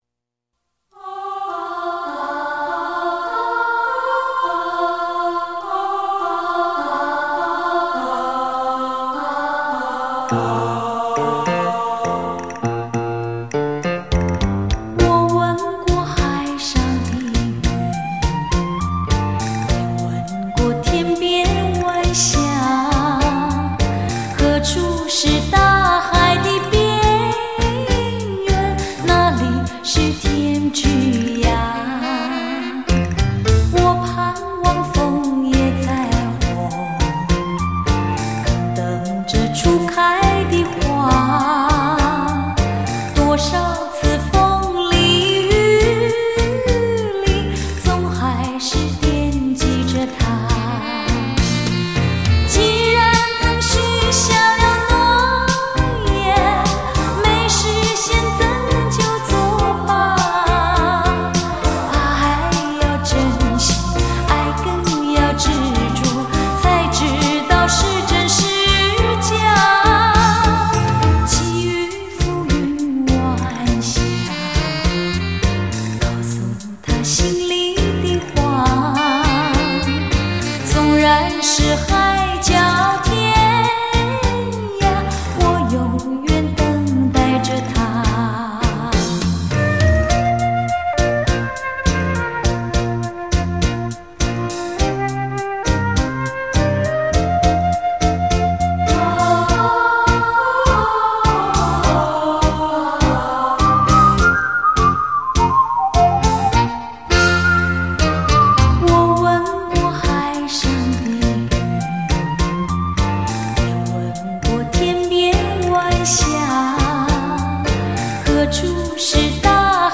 探戈